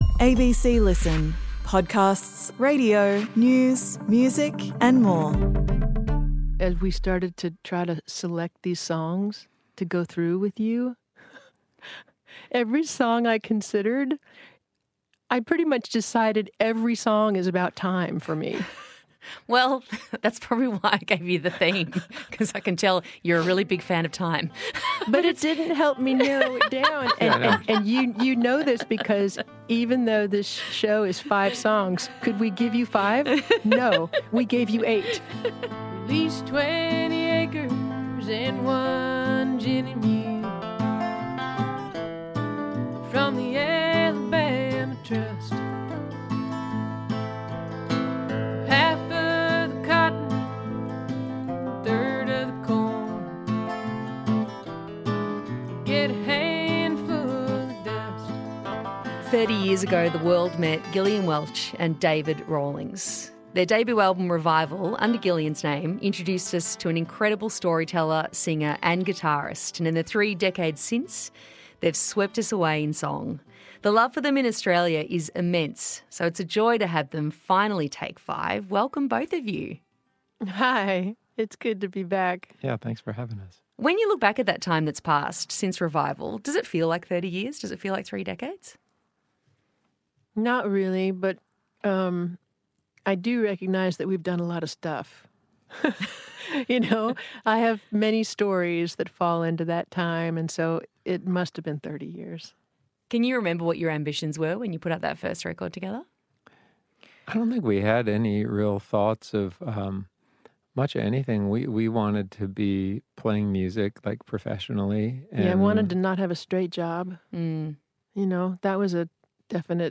Settle in for a deep, insightful, and intoxicating conversation with two of music's best.